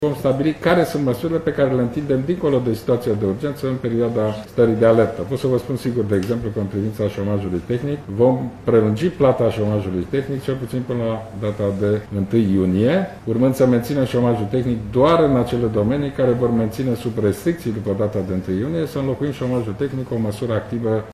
Guvernul va adopta joi o ordonanță de urgență care va prelungi acordarea șomajului tehnic până la 1 iunie pentru firmele afectate de criză și care nu-și vor relua activitatea, a declarat premierul Ludovic Orban:
Declarațiile premierului au fost făcute în județul Argeș.